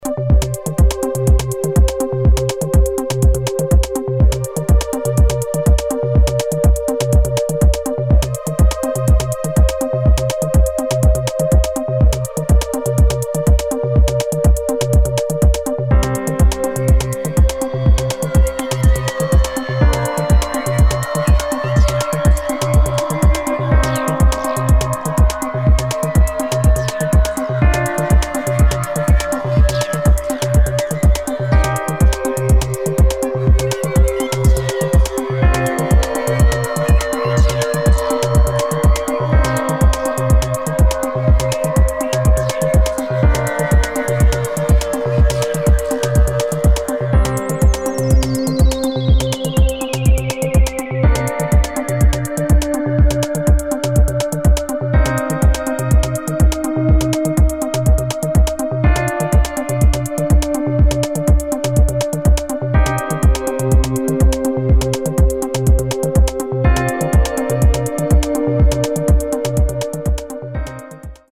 [ DEEP HOUSE ]